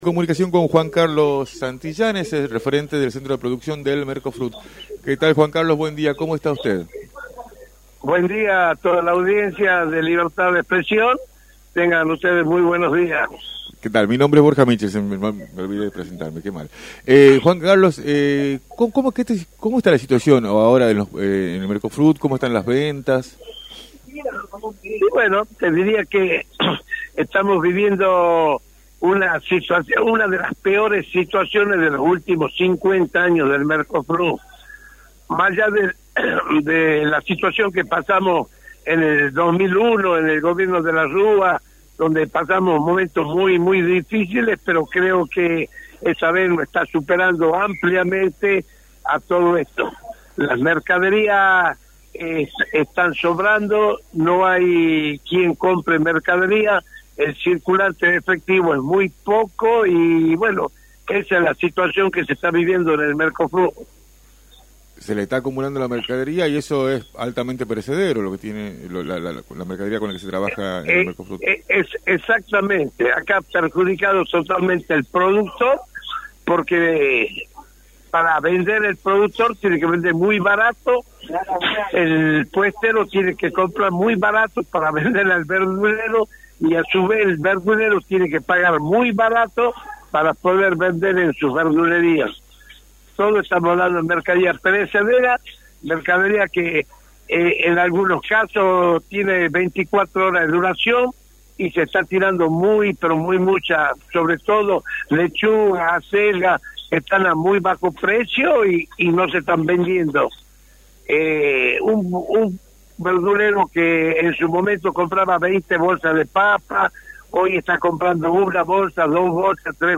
en entrevista para “Libertad de Expresión”, por la 106.9.